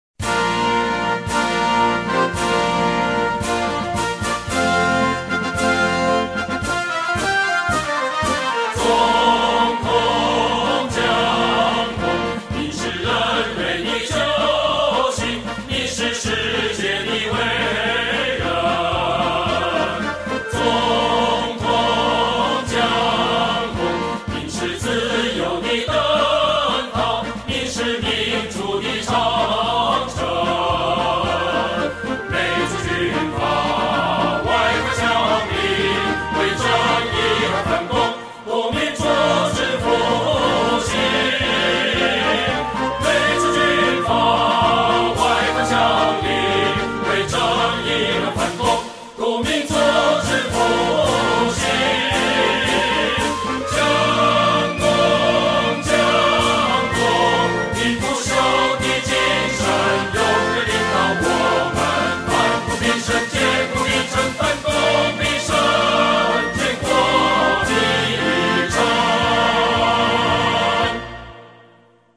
國歌